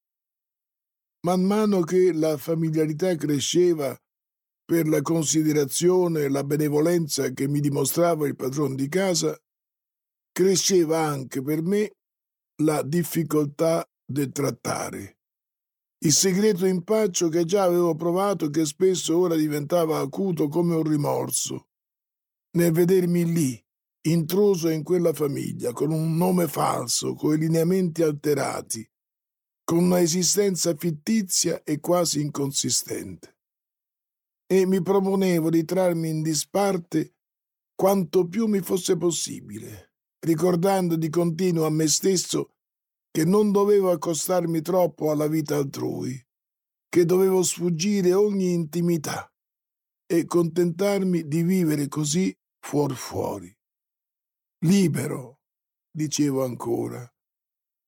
Audiolibro Emons Audiolibri 2017